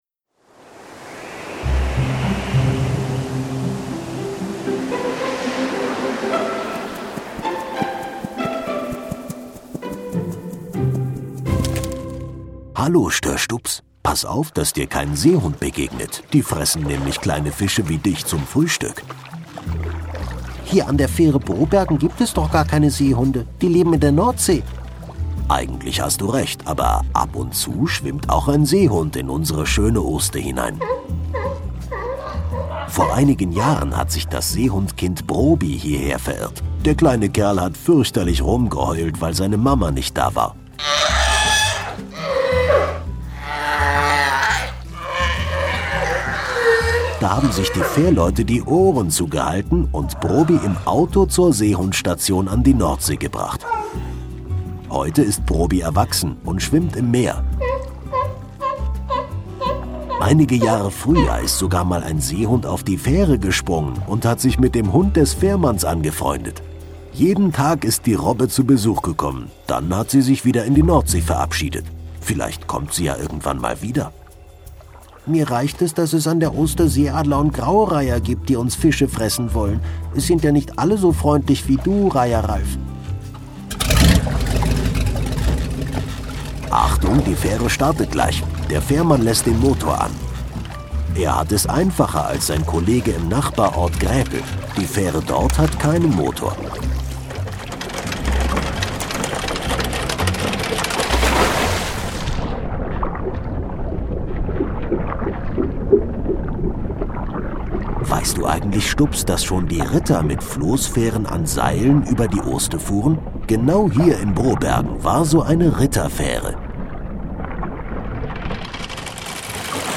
Prahmfähre Brobergen - Kinder-Audio-Guide Oste-Natur-Navi